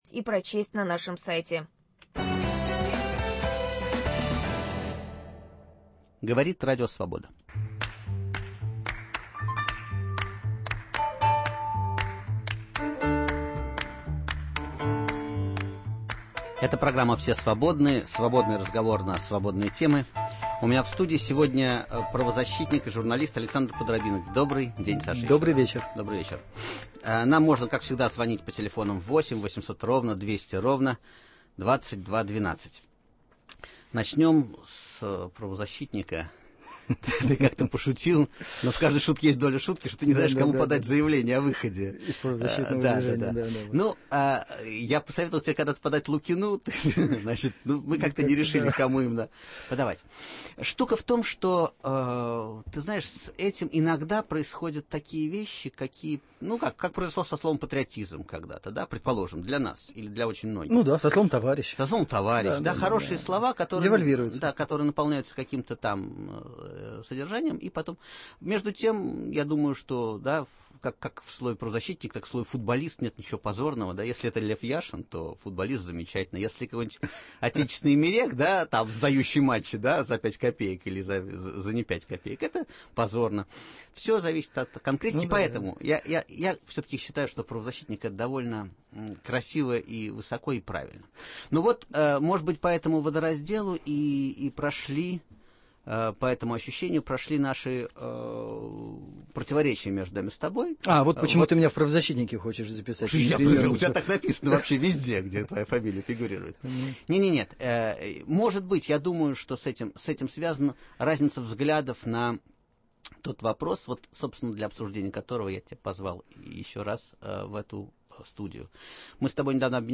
Свободный разговор на свободные темы. Гостей принимает Виктор Шендерович, который заверяет, что готов отвечать на любые вопросы слушателей, кроме двух: когда он, наконец, уедет в Израиль и сколько он получает от ЦРУ?